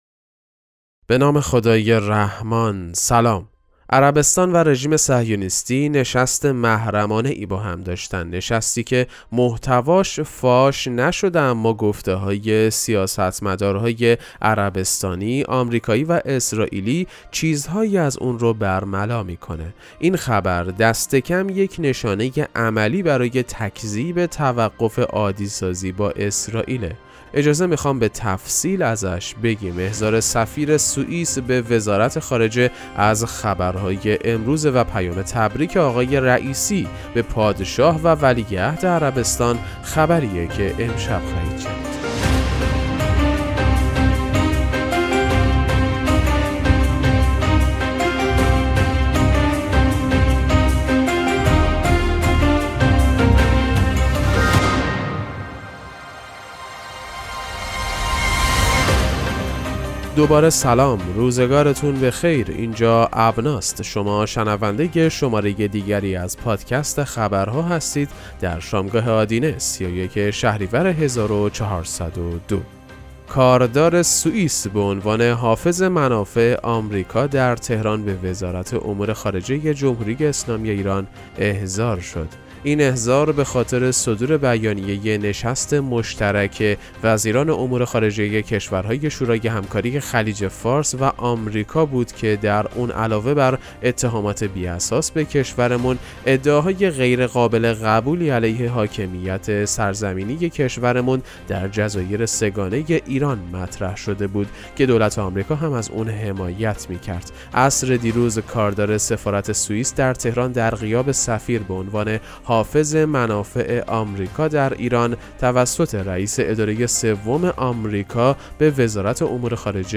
پادکست مهم‌ترین اخبار ابنا فارسی ــ 31 شهریور 1402